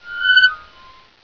game-source/ParoxysmII/sound/ambience/sqeak2.wav at master
sqeak2.wav